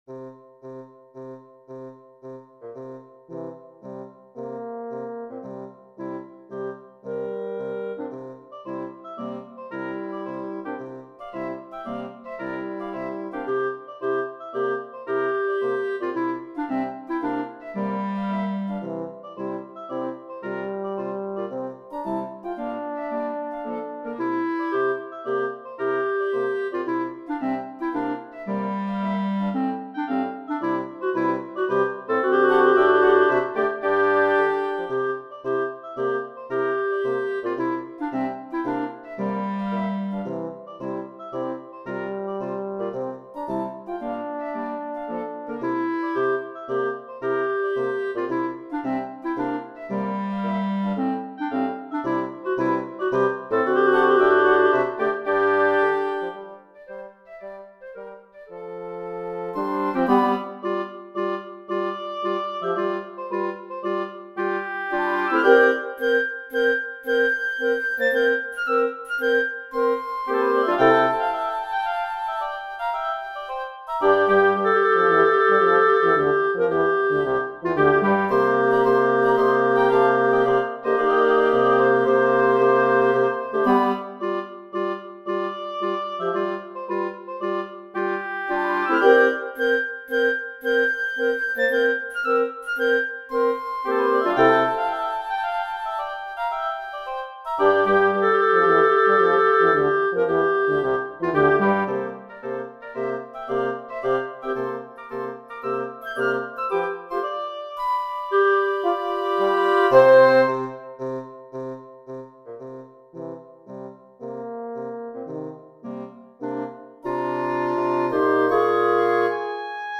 Wind Quintet Edition